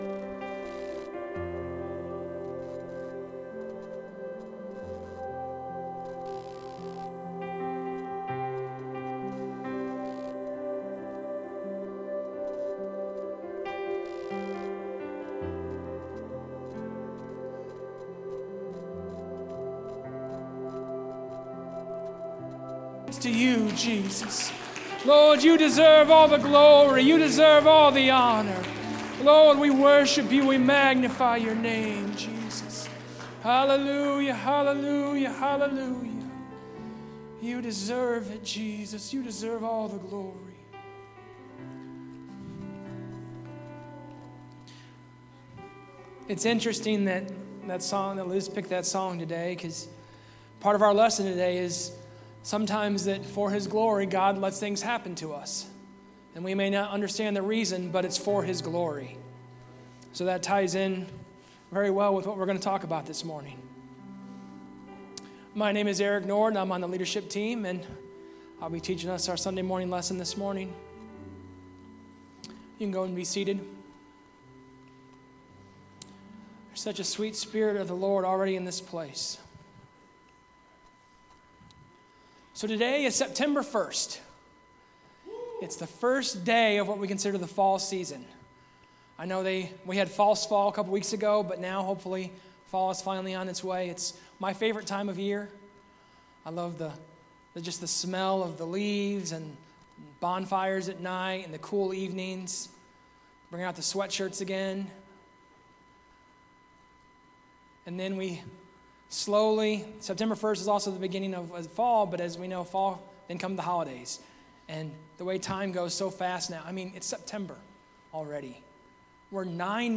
Sermons | Elkhart Life Church
Sunday Service - Part 6